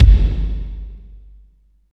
32.10 KICK.wav